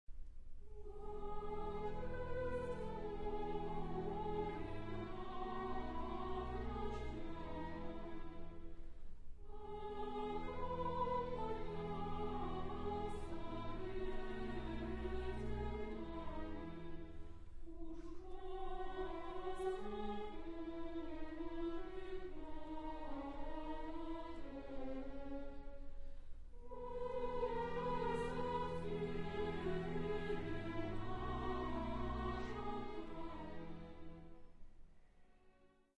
Persian Chorus